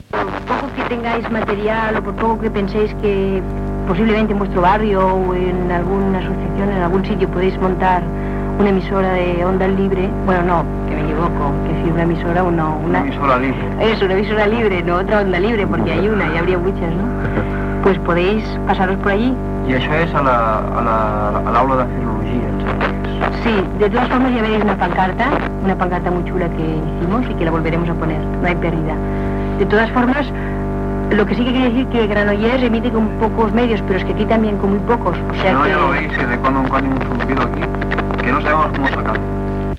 Anunci de l'assemblea a la Universitat de Barcelona, després del primer tancament, i invitació a crear ràdios lliures amb esment a la de Granollers (Ràdio Maduixa).